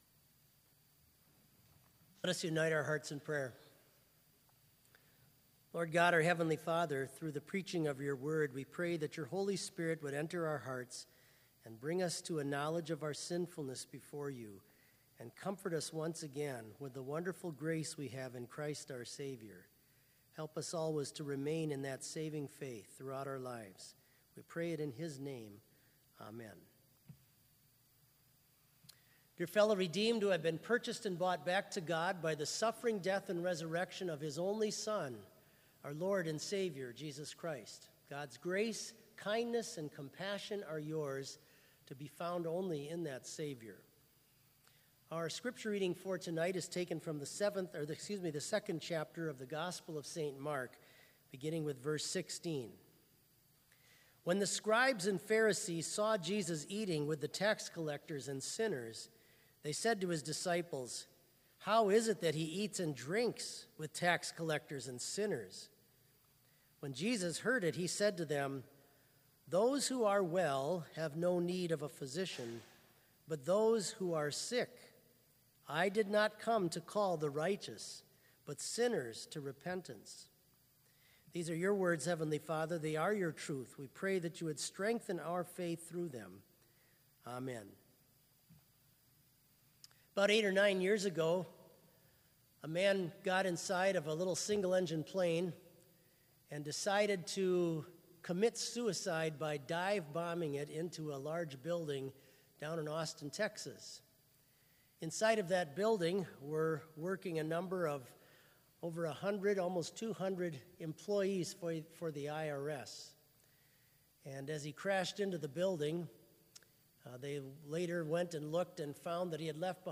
Vespers worship service
BLC Trinity Chapel, Mankato, Minnesota
Complete service audio for Vespers - September 4, 2019